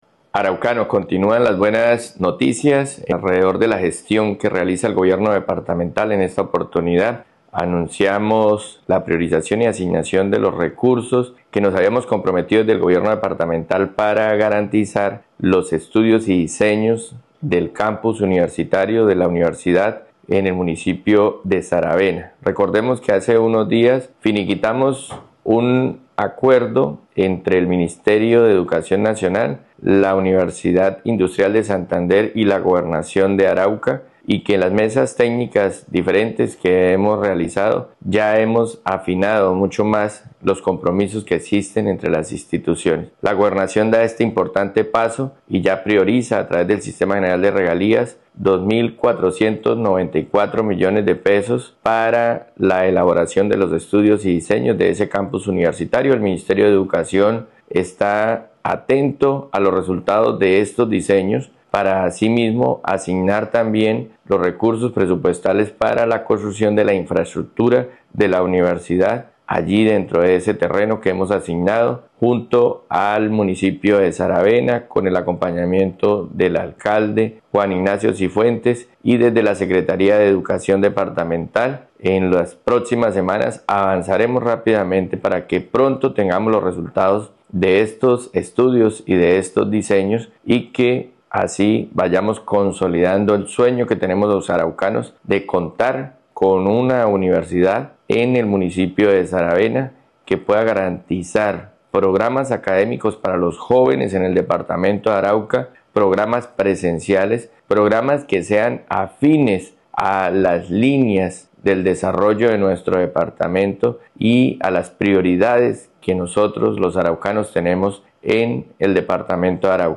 por Renson Martínez Prada, gobernador departamnto de Arauca
Anuncio-del-gobernador-de-Arauca-Inversion-Campus-Universitario-Saravena.mp3